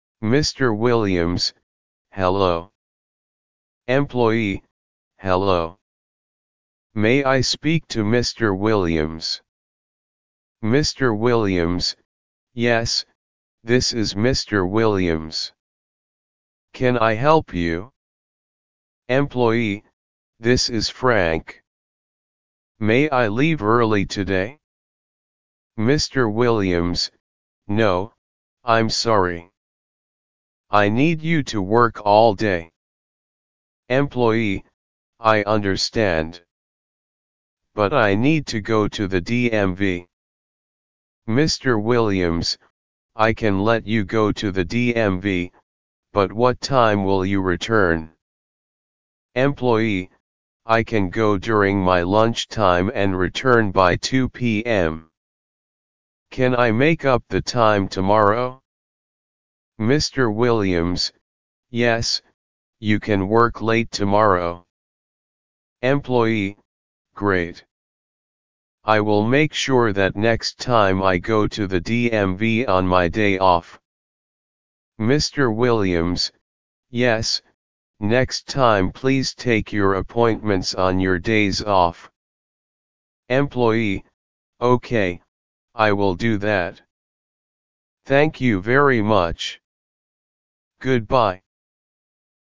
Level 2 Conversation